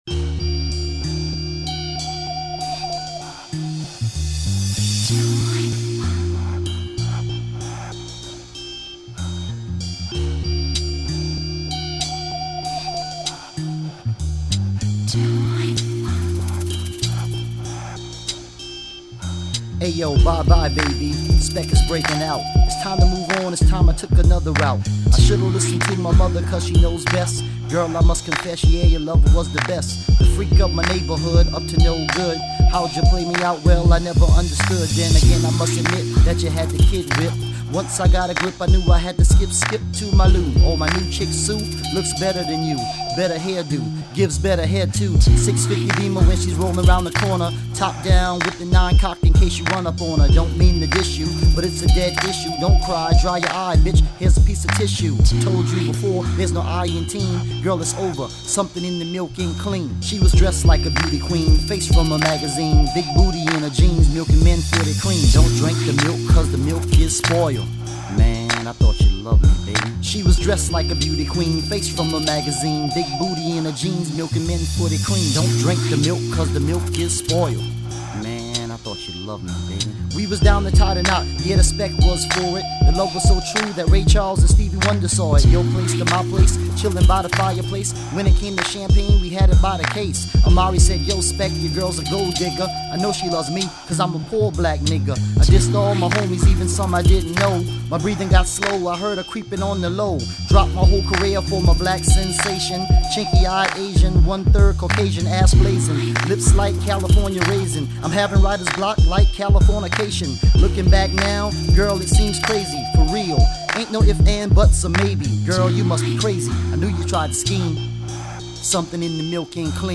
but nice and laid back….enjoy